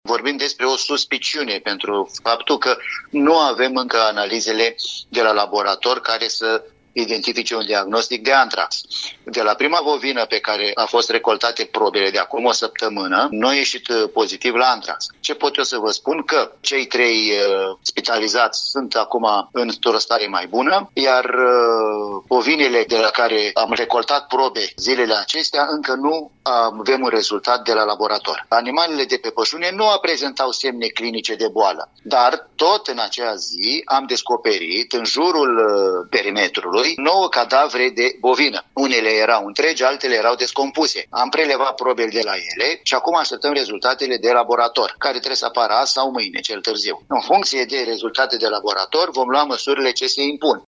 Directorul Direcției Sanitar Veterinare Iași, Gabriel Ciobanu a declarat că a fost înaintată o sesizare la Inspectoratul Județean de Poliție Iași.